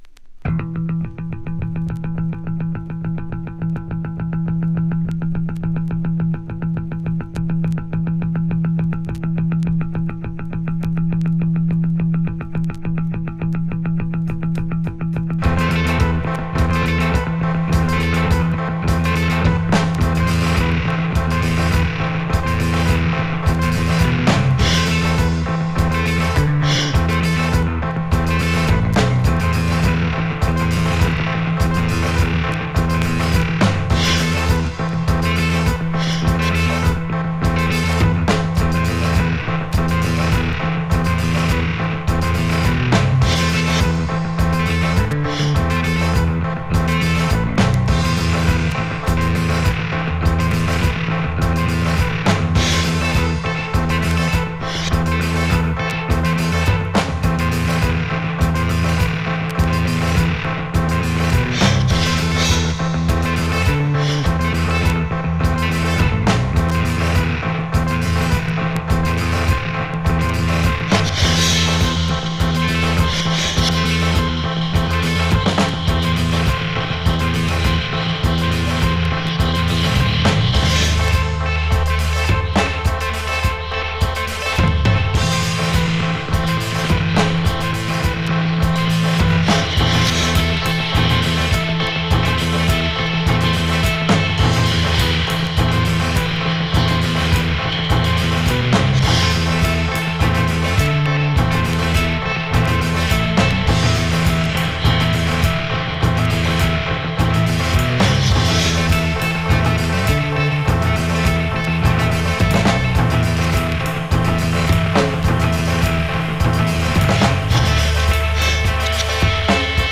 1996年にカナダ・モントリオールで結成された実験的なインストゥルメンタルロックバンドの3rd album。